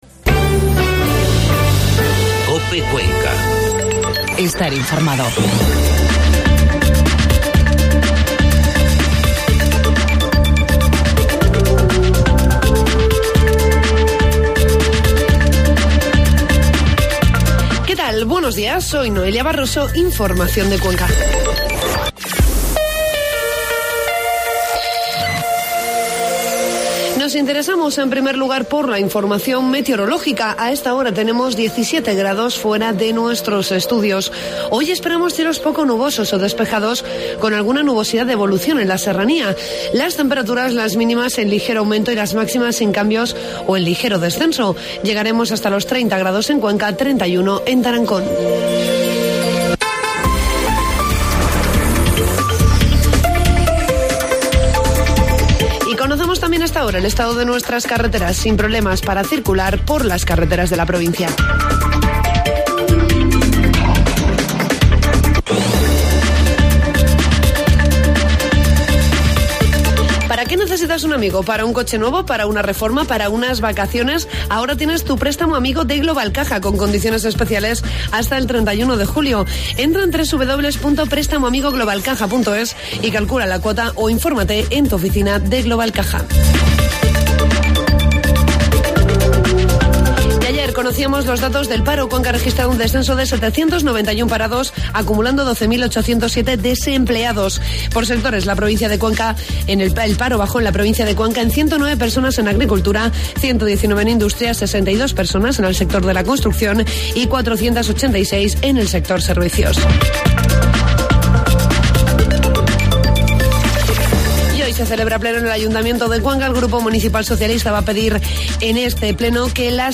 AUDIO: Informativo matinal 20 de julio